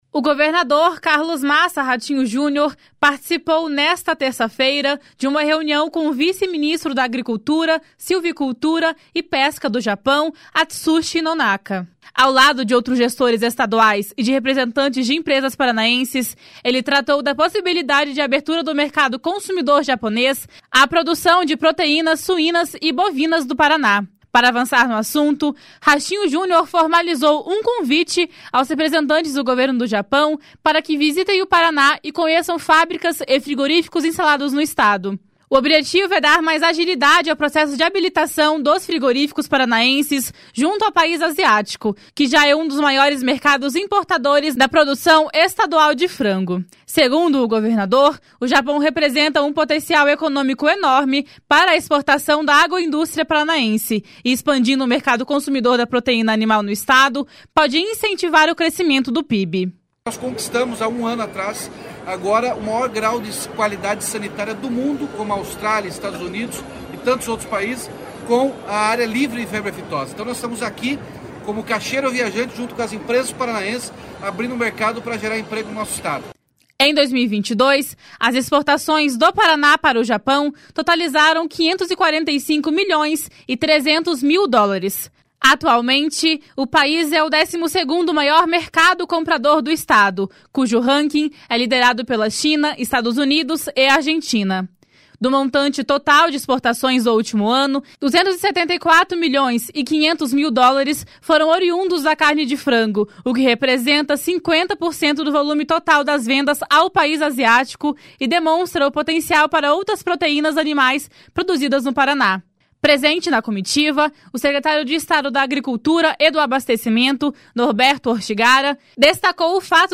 // SONORA RATINHO JUNIOR //
// SONORA NORBERTO ORTIGARA //